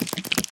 MinecraftConsoles / Minecraft.Client / Windows64Media / Sound / Minecraft / mob / spider / step4.ogg
step4.ogg